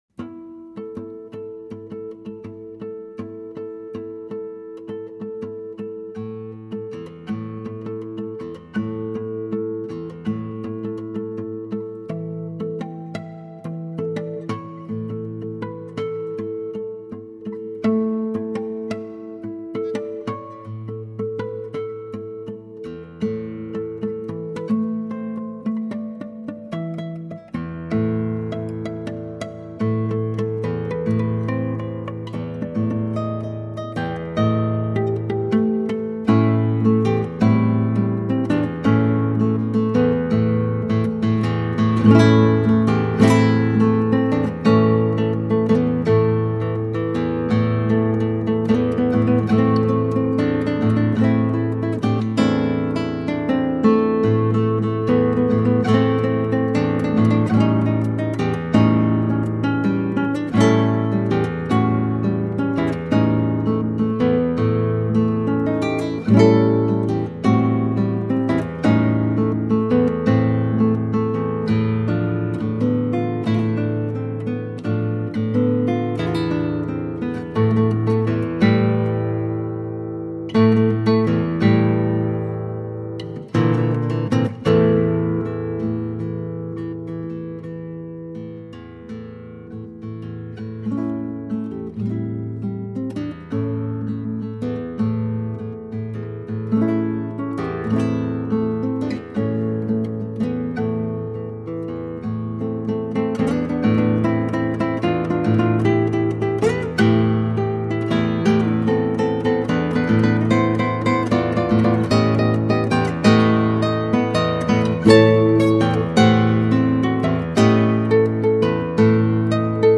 Instrumentação: violão solo
Tonalidade: Am | Gênero: ijexá